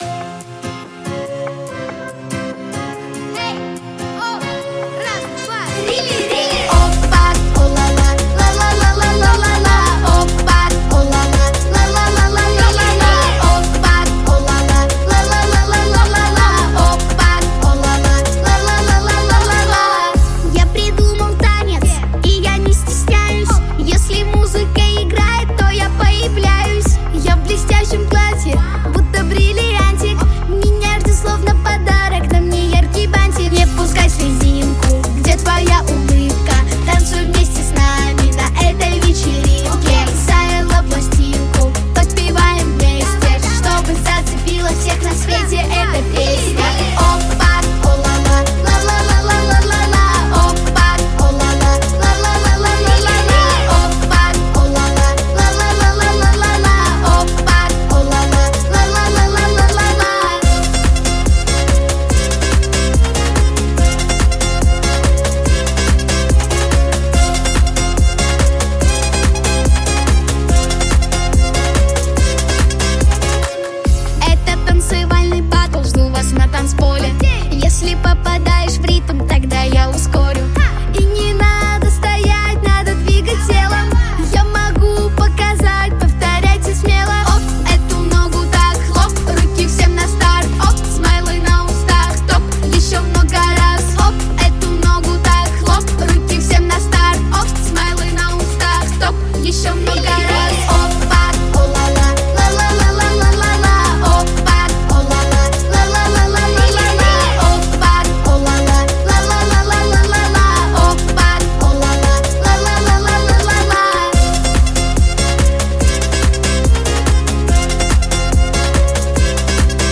• Жанр: Детские песни
детская дискотека, вечеринка, танцевальная
в исполнении девушек